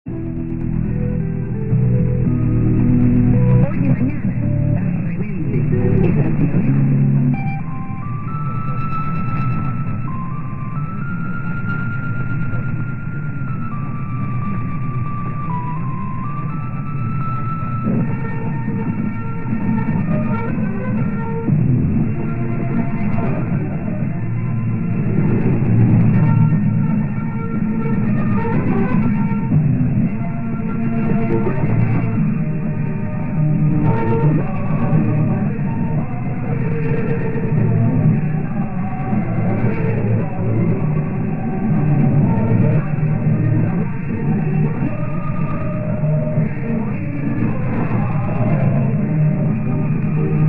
Radio Rebelde - Midnight lullaby and choral national anthem.